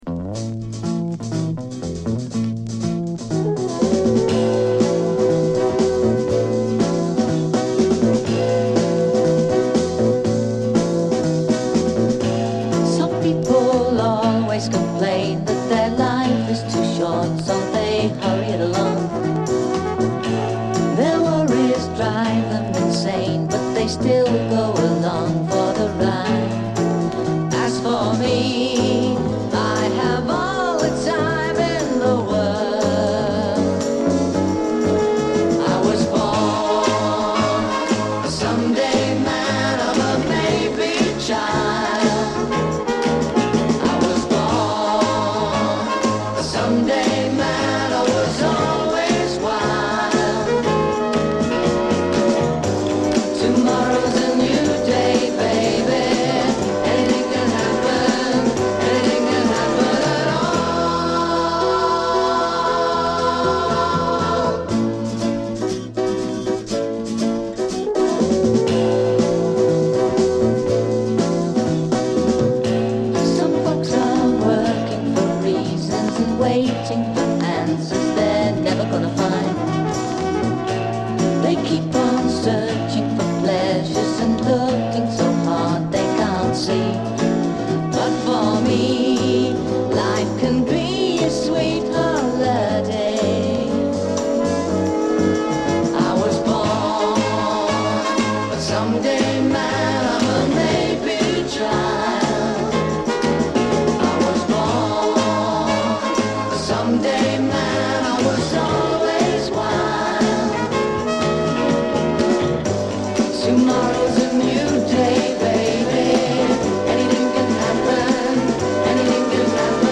によるソフトロック最高曲